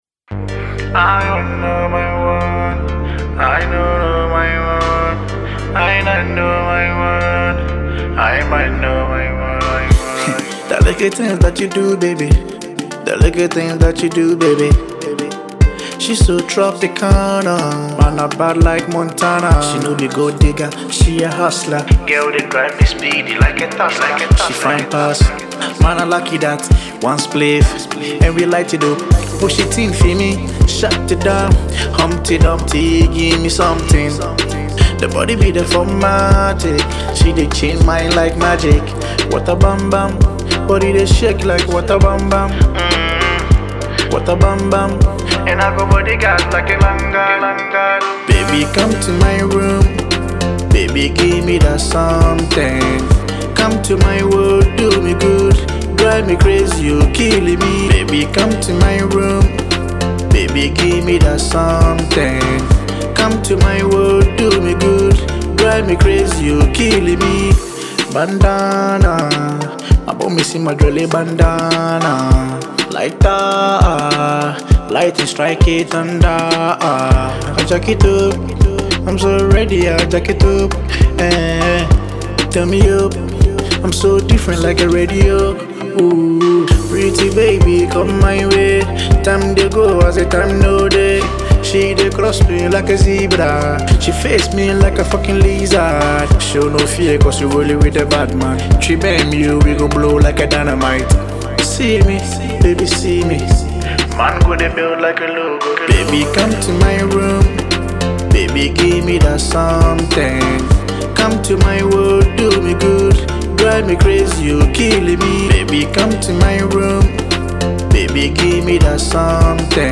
Ghana MusicMusic
groovy and fast tempo song